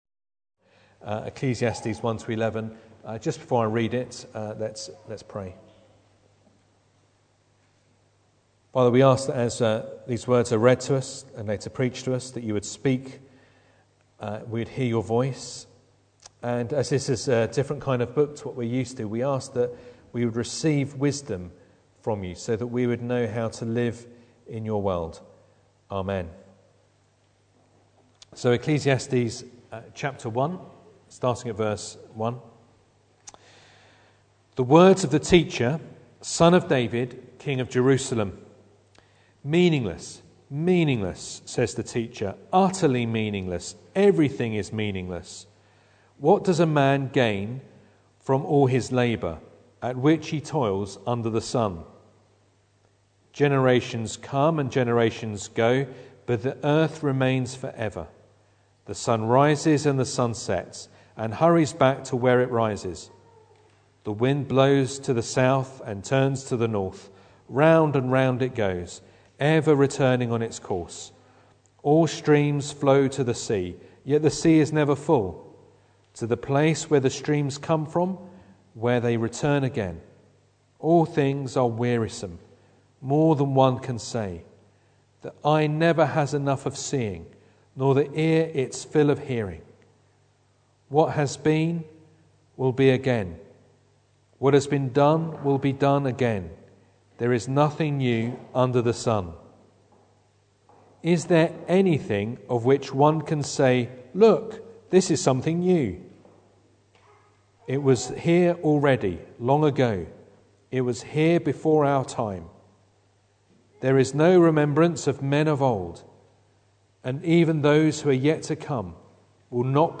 Ecclesiastes 1:1-11 Service Type: Sunday Evening Bible Text